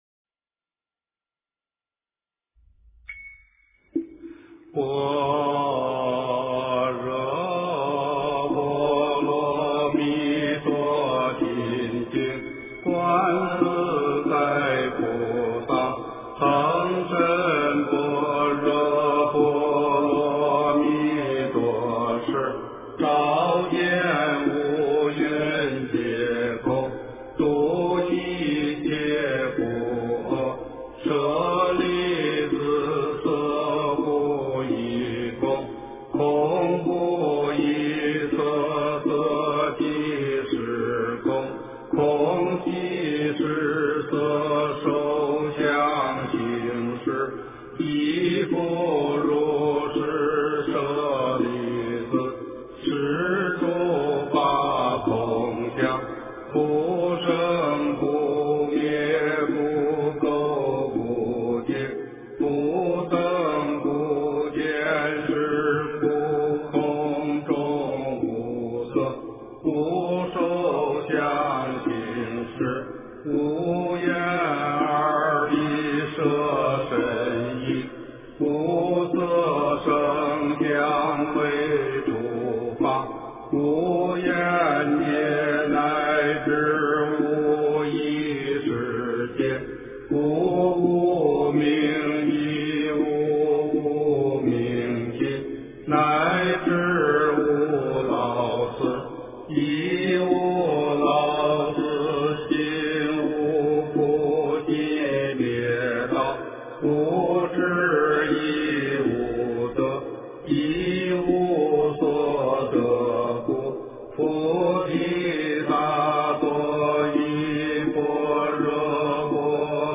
诵经
佛音 诵经 佛教音乐 返回列表 上一篇： 般若波罗蜜多心经 下一篇： 地藏经-忉利天宫神通品第一 相关文章 春苗-古筝--未知 春苗-古筝--未知...